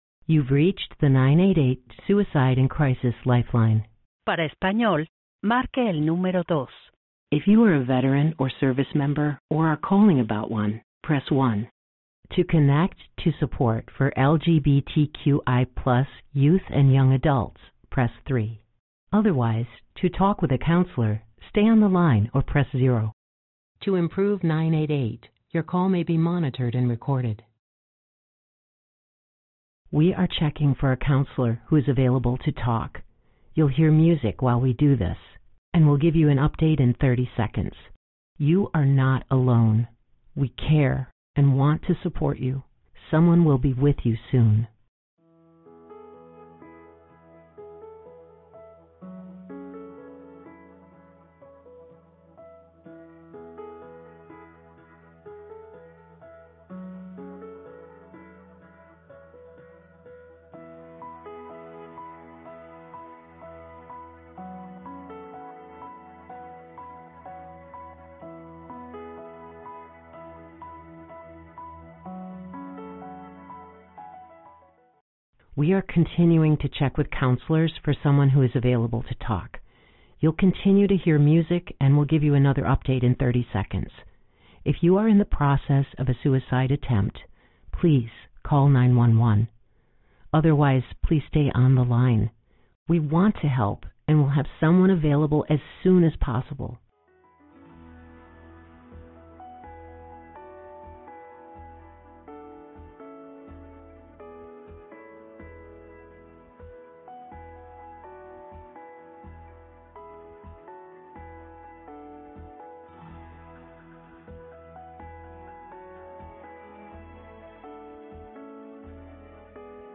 2. A message will play (